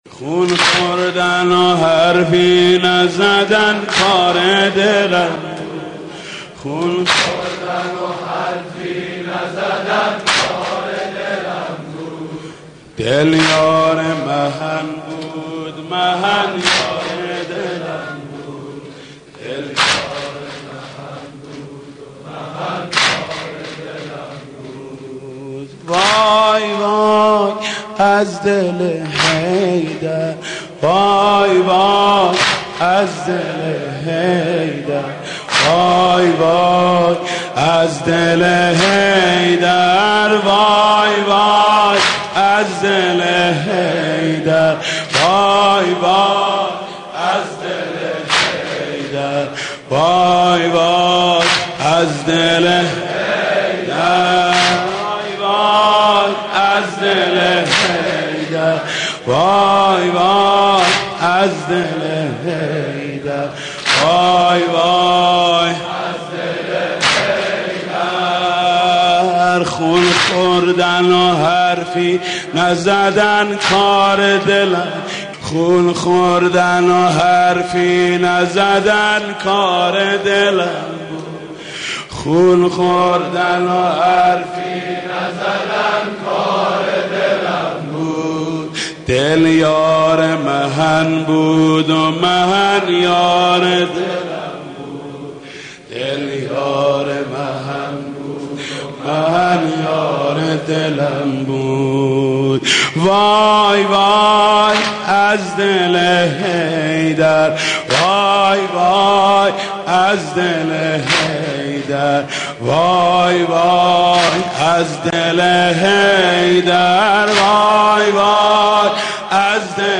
دانلود مداحی شهادت حضرت زهرا (س) ایام فاطمیه حاج محمود کریمی